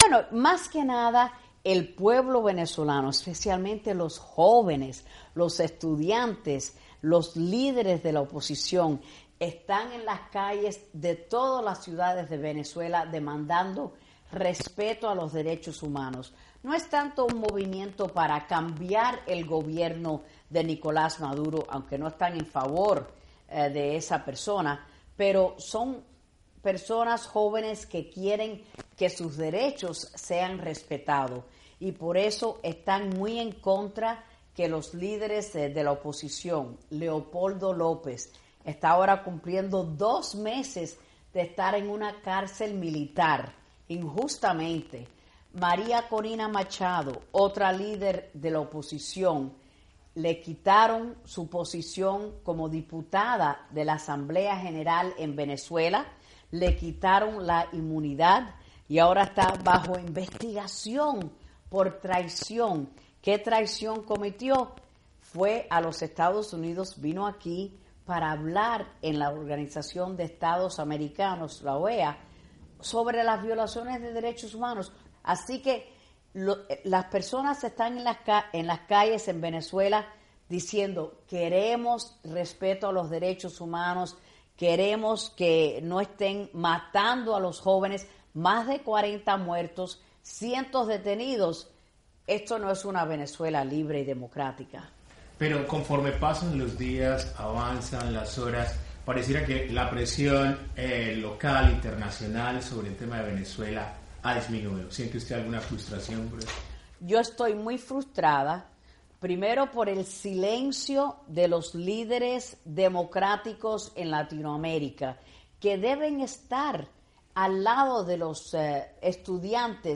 ENTREVISTA REPRESENTANTE ILEANA ROS-LEHTINEN